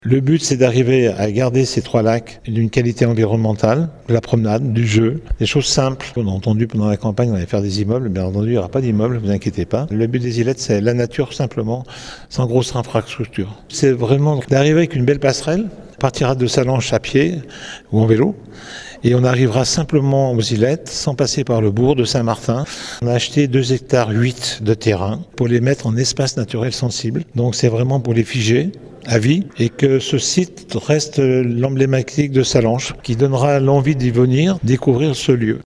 Georges Morand le maire de Sallanches s’en explique